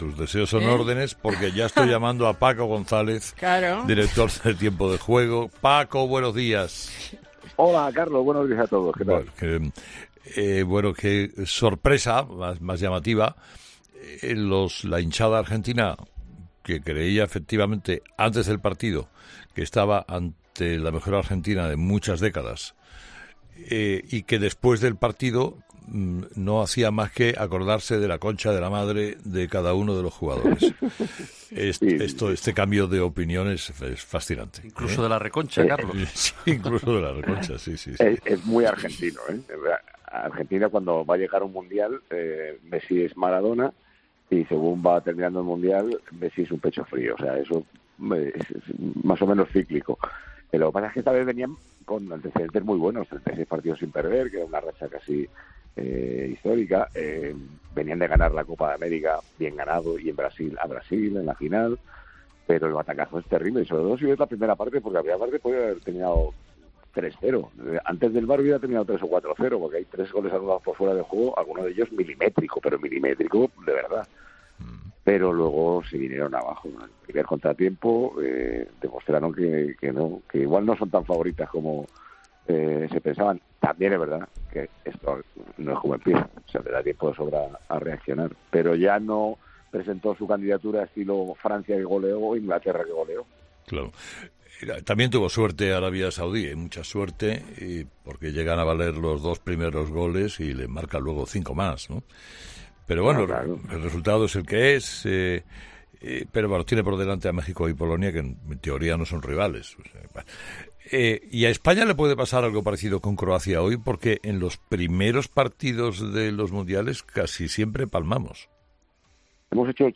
El director de 'Tiempo de Juego' ha charlado con Carlos Herrera en el día del debut de España en el Mundial de Qatar
Escucha a Paco González hablar sobre España en su debut en el Mundial de Qatar